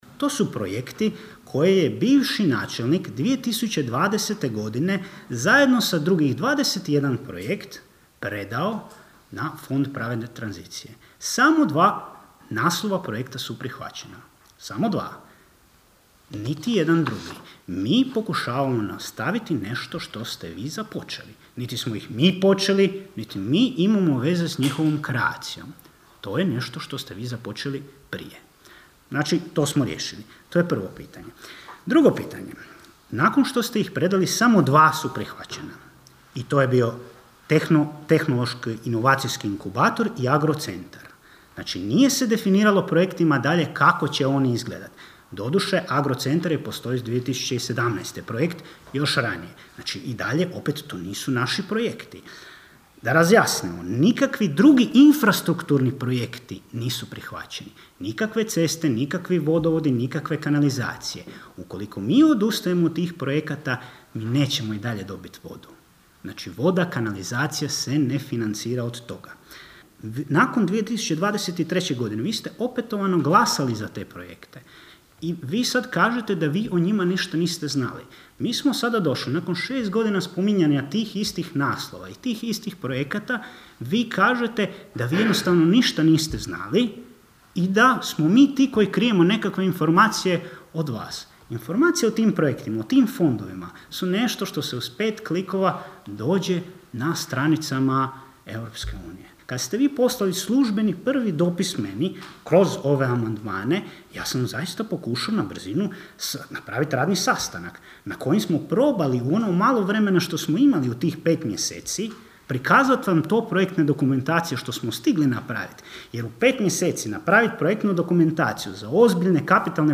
Sjednica Općina Kršan
Odgovarajući Uraviću, predsjednik Vijeća Ivan Zambon kazao je kako ti projekti za prijavu na Fond pravedne tranzicije nisu njihovi projekti: (